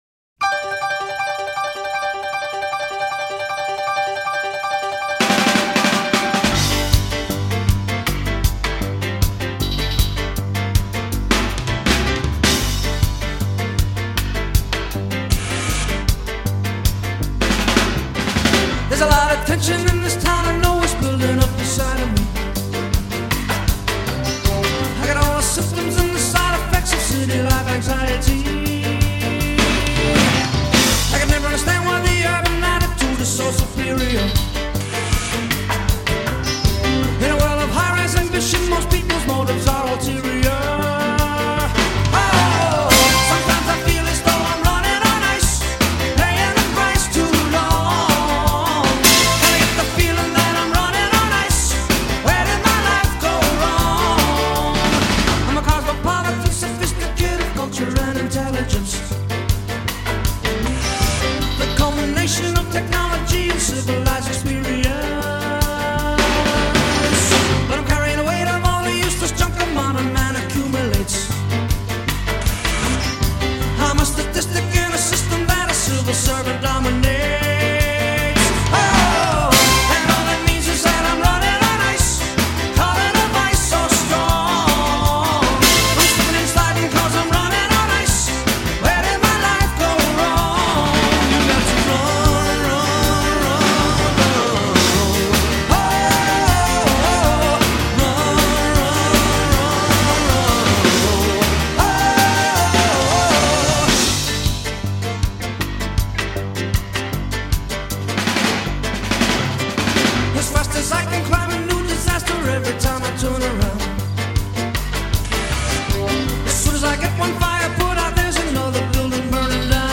twitchy lyrical joyride